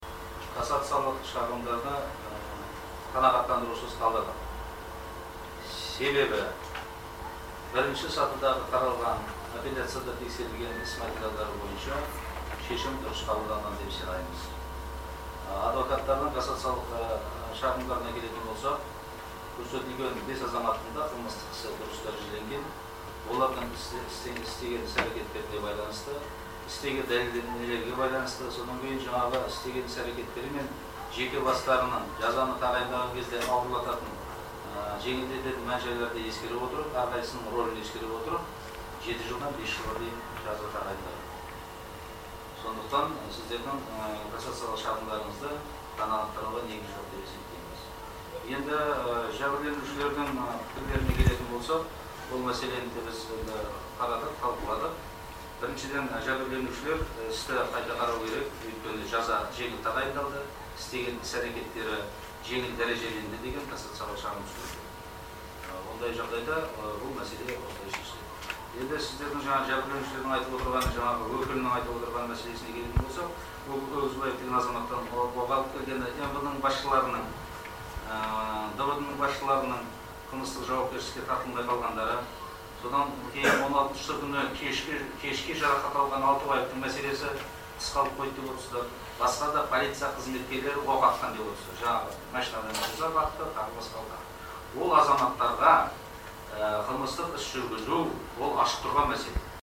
Судья Досжан Әміровтің сөзі